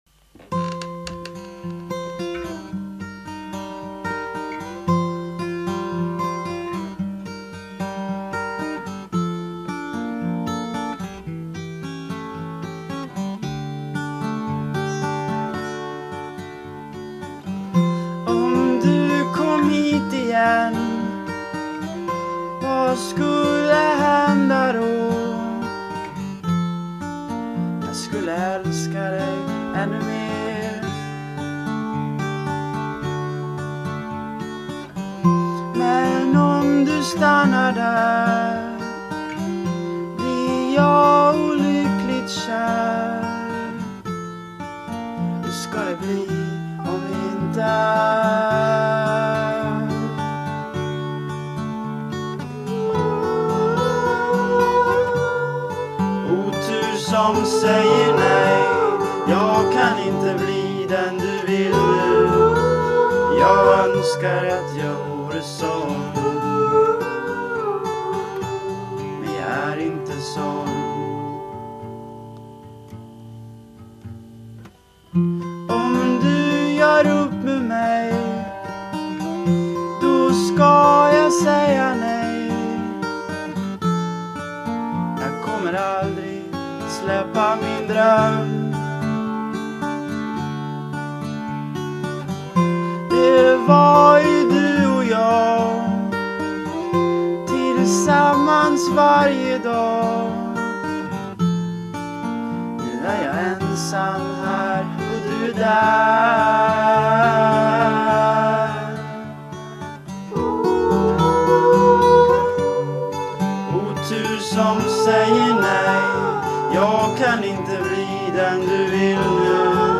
Är ett svenskt popband som sjunger låtar på svenska.